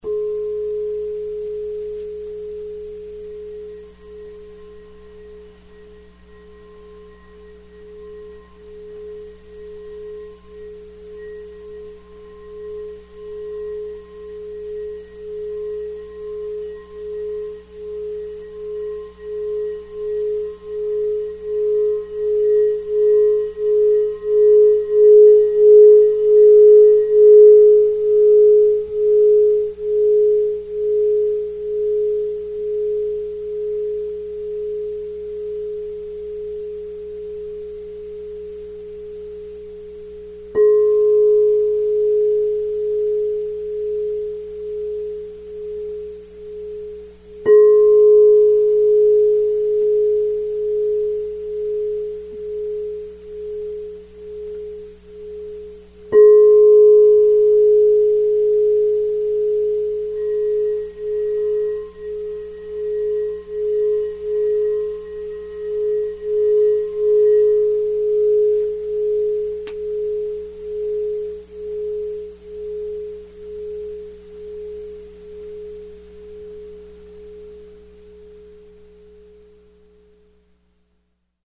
Singing Bowls
The internal spiral structure and the external rounded form of Crystal Singing Bowls produce a non linear, multidirectional Sound.
This process makes the bowls strong and incredibly pure in tone.
Son_Chakra_G.mp3